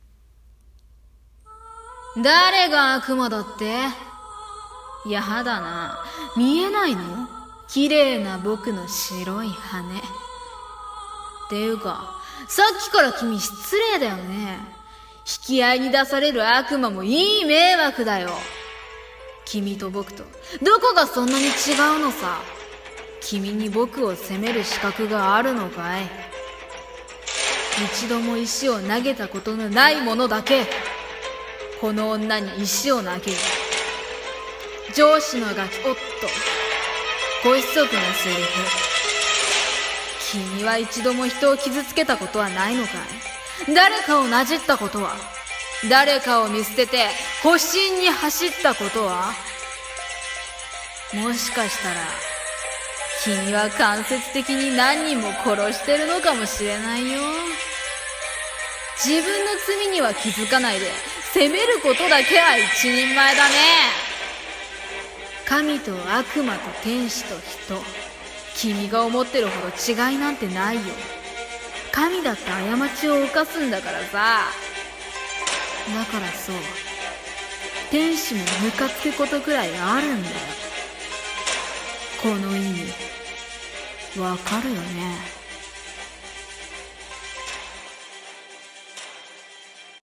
【声劇】天使の言い分